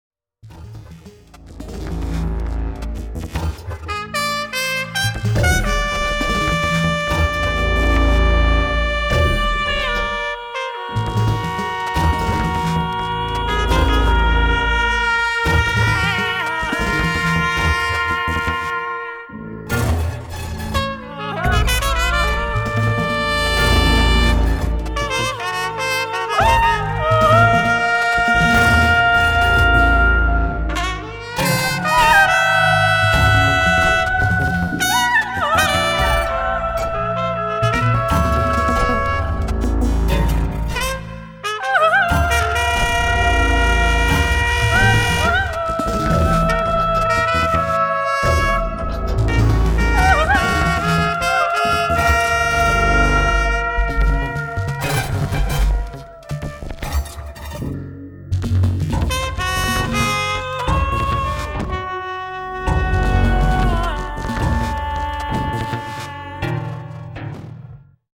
na trąbkę, głos i komputer